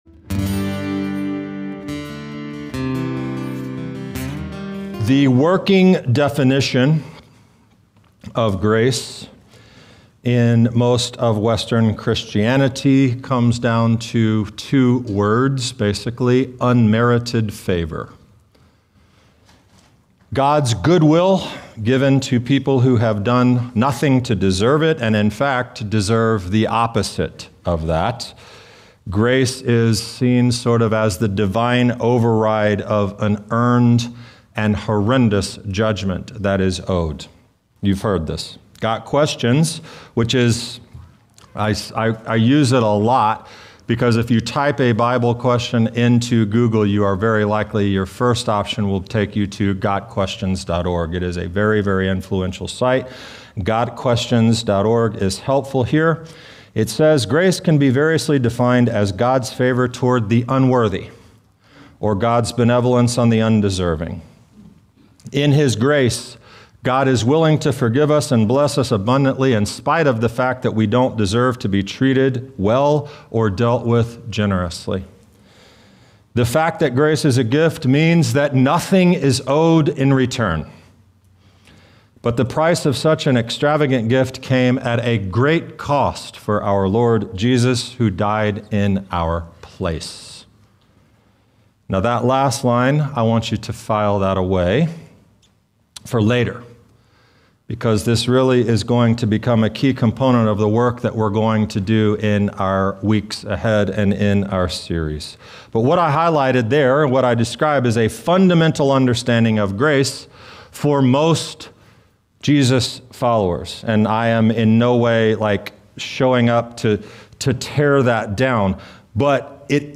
Looking closely at Moses and Israel after the golden calf, this teaching shows that divine favor was already operating in Scripture in ways many believers have never fully considered. Atonement Explained is a biblical teaching series designed to help viewers rethink what sacrifice and atonement really mean.